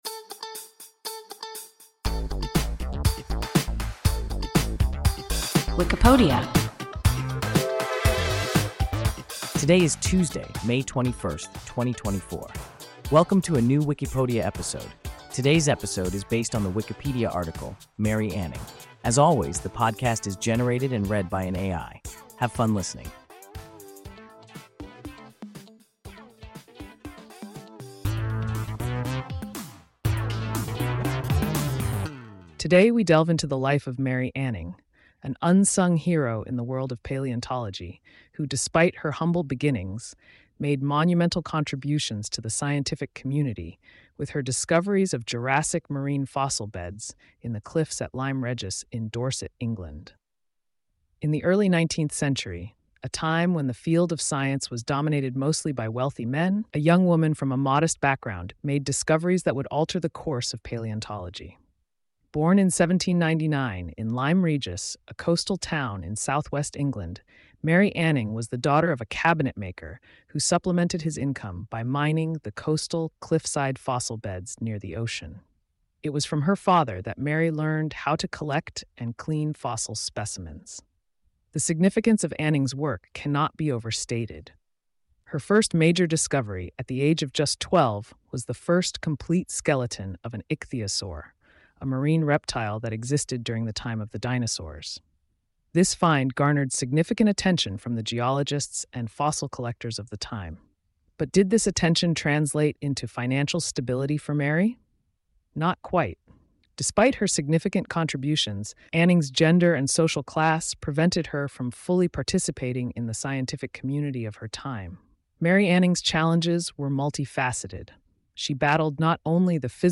Mary Anning – WIKIPODIA – ein KI Podcast